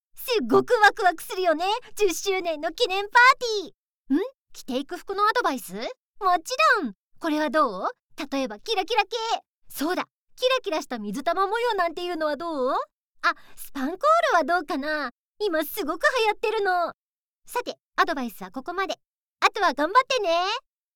優しく、誠実な声が特徴です。
おしゃべりな女子
female05_47.mp3